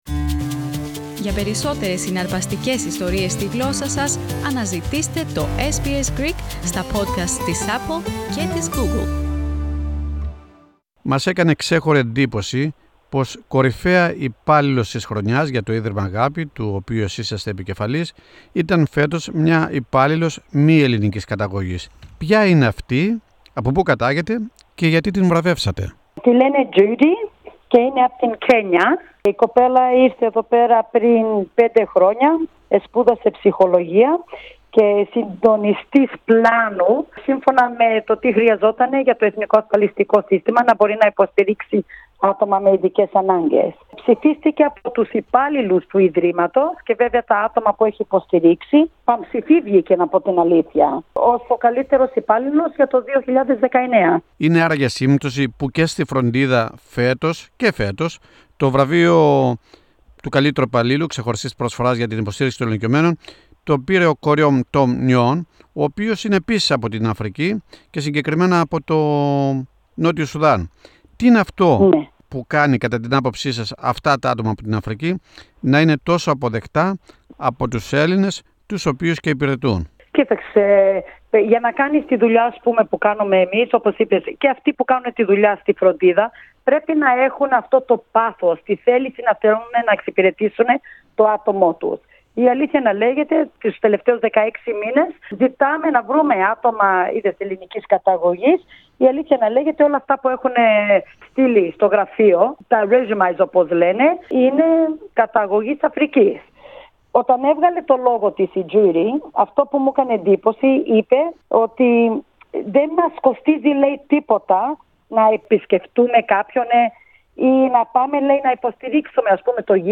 συνέντευξή